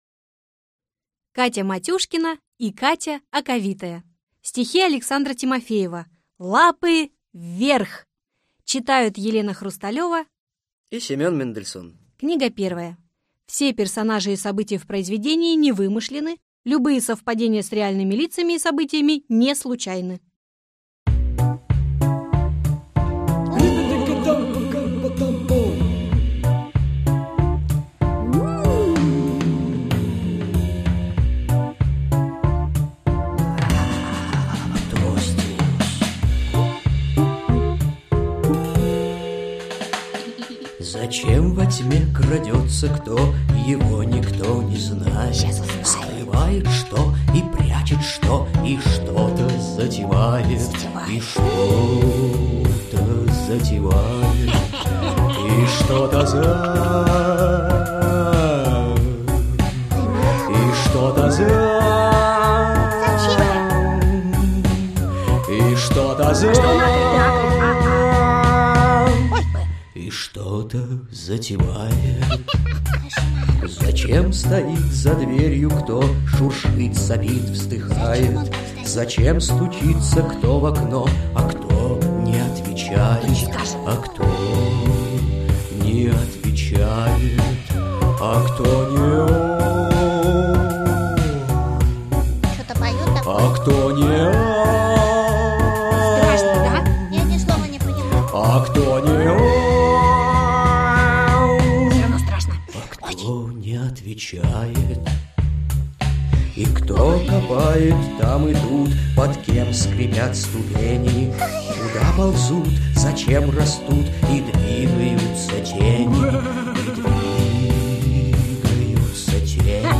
Аудиокнига Лапы вверх!